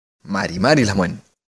Respecto a la expresión 5), «mari mari«, su pronunciación es más suave que su símil en otras variantes de la lengua mapuche como el mapuzungun, mapudungun, chedungun, entre otras.